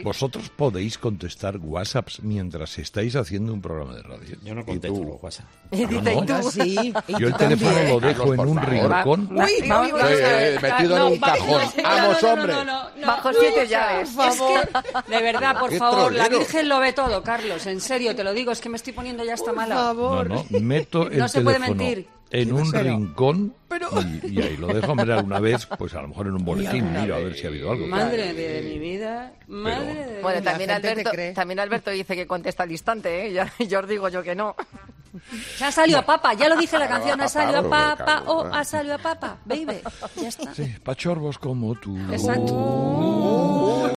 Durante la tertulia, Carlos Herrera se ha sincerado con sus oyentes sobre lo que hace en Herrera en COPE